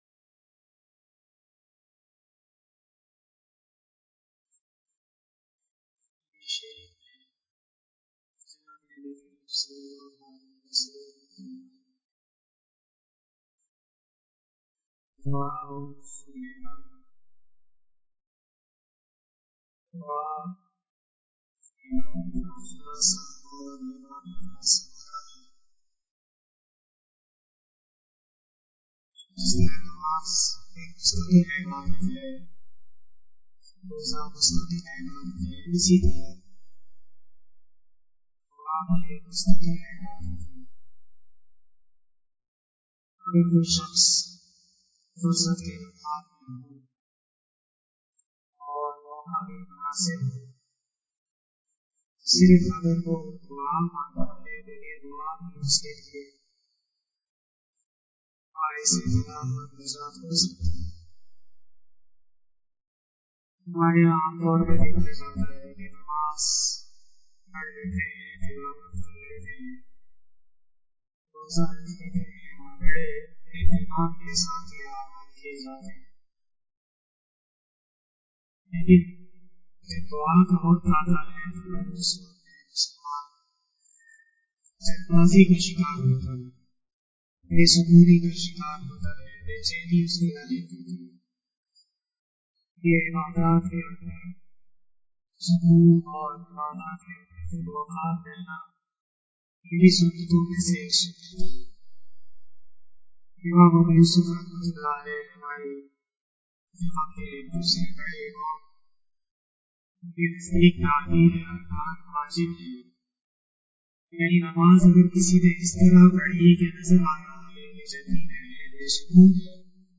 014 After Asar Namaz Bayan 22 April 2021 ( 09 Ramadan 1442HJ) Thursday